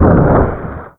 DEMOLISH_Short_06_mono.wav